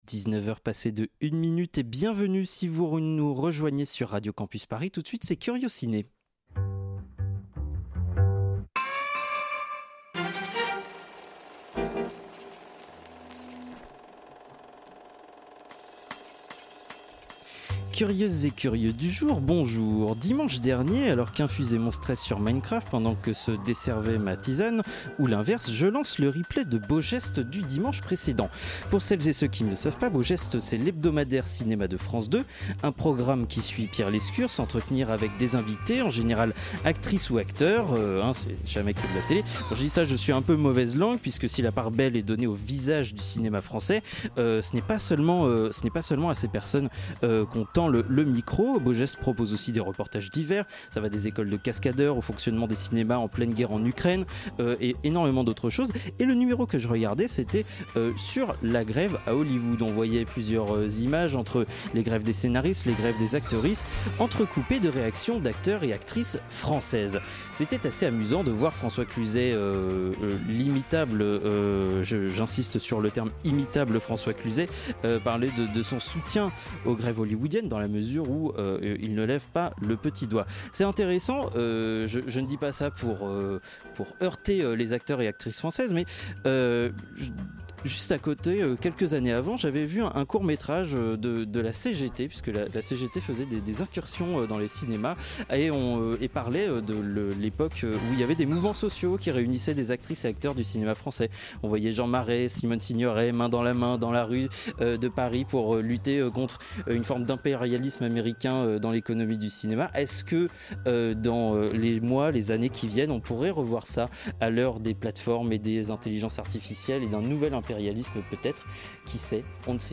Magazine Culture